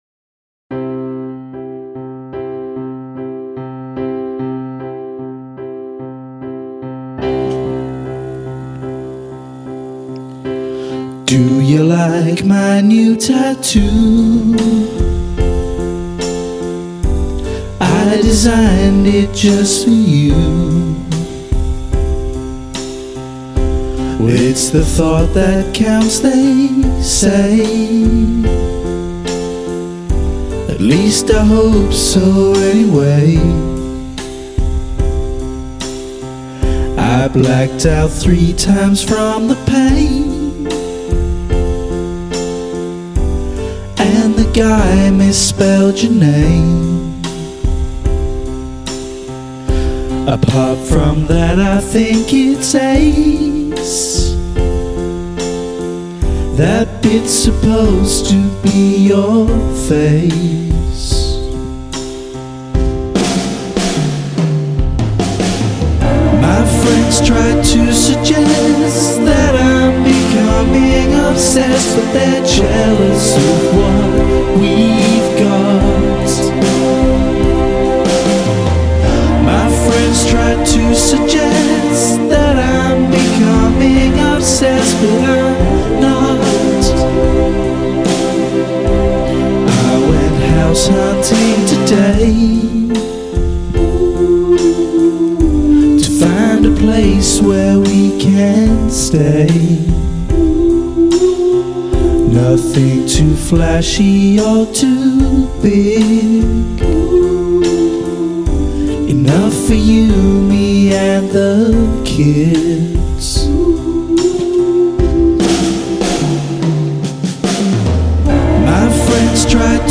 A power ballad.